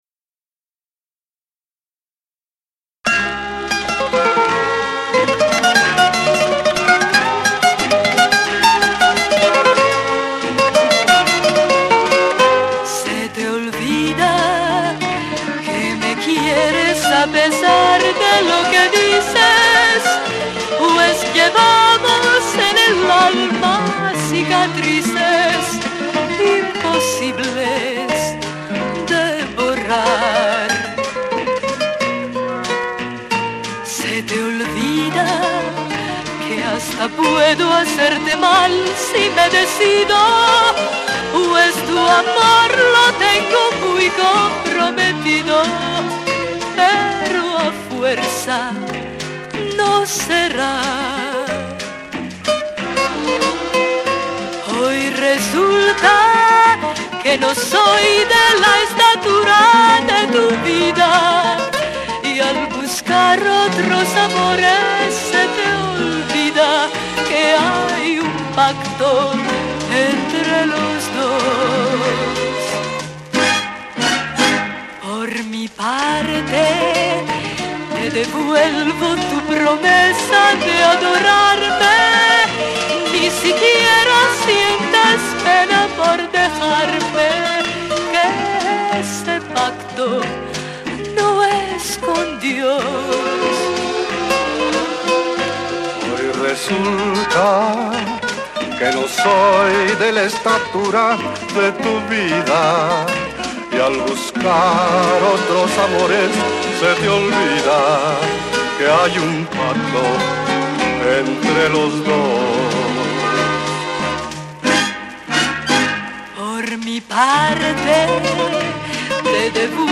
the wonderful voices and guitars of the famous trio